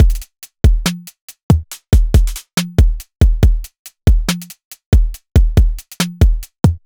Index of /99Sounds Music Loops/Drum Loops/Hip-Hop